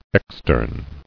[ex·tern]